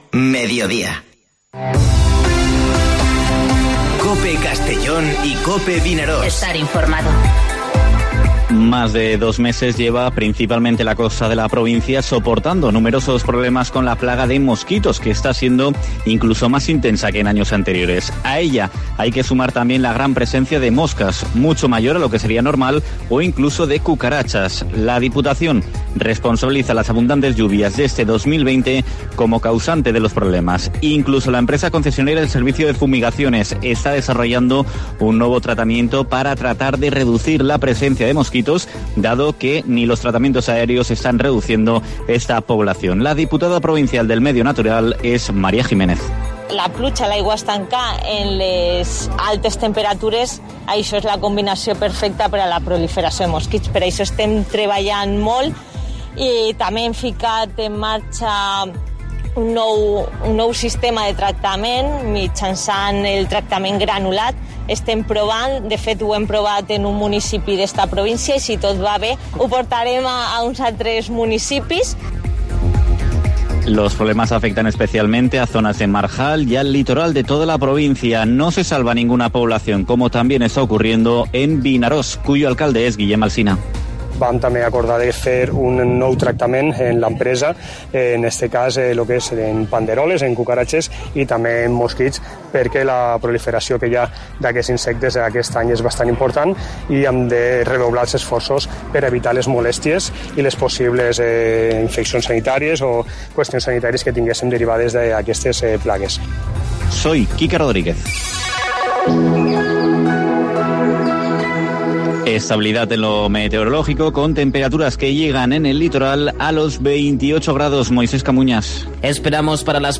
Informativo Mediodía COPE en la provincia de Castellón (22/06/2020)